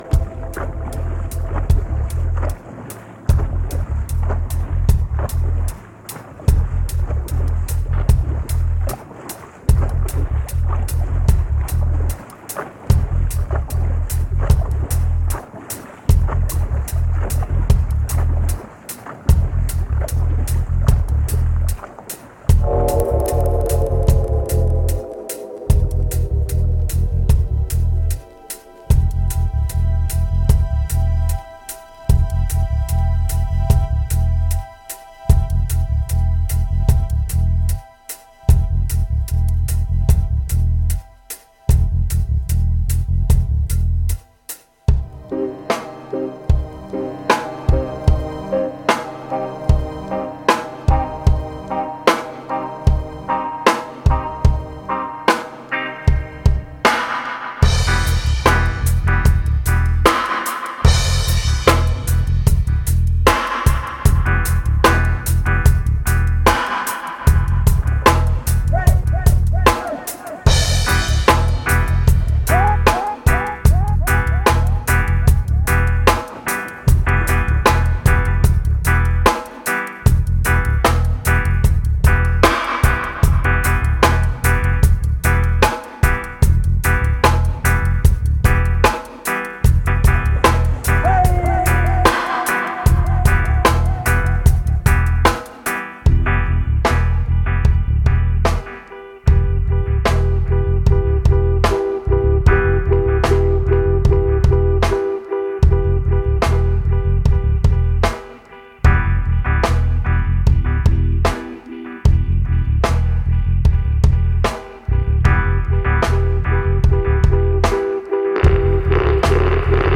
Genre: Dub.